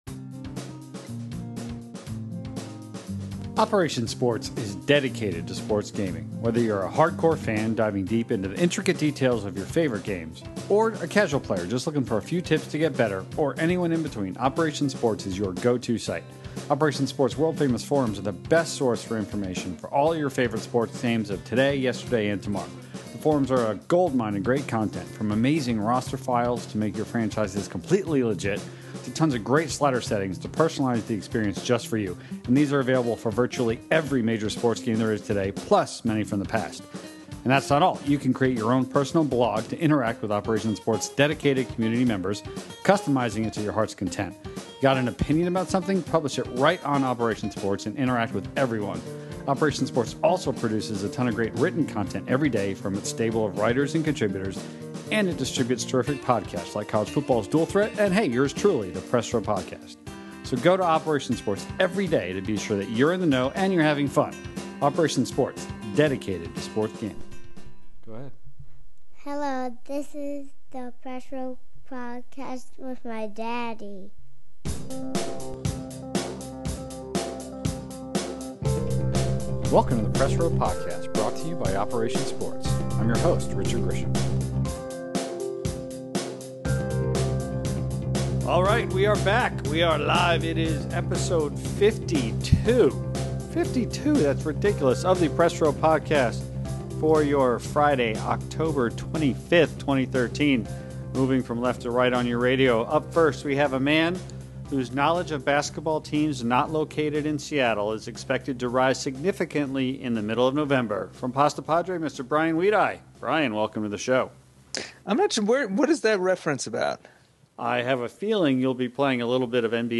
Episode 52 of the Press Row Podcast features a spirited panel breaking down all the new next-generation information as well as creating some on-the-fly wishlists for sports experiences on the PS4 and Xbox One.